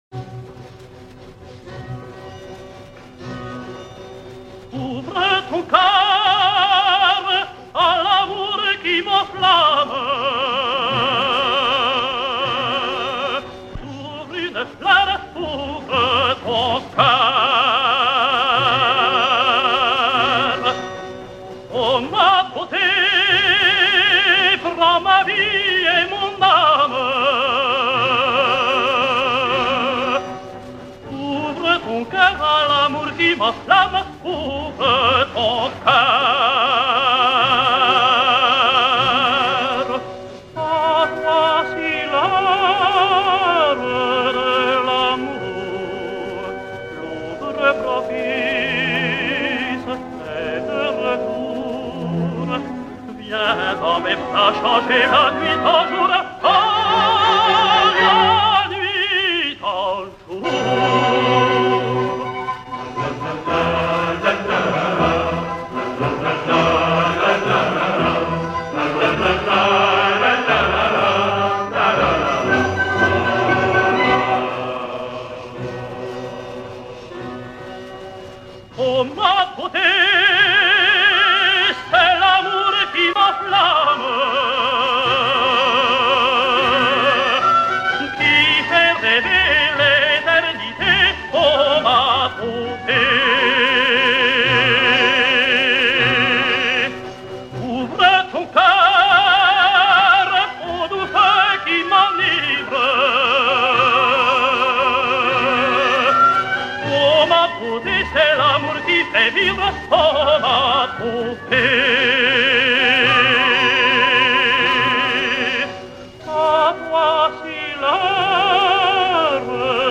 André Mallabrera singsIvan IV: